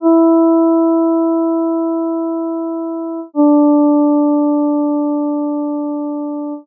Both voices move in whole notes. The second is an octave below the first.
voice1: E D +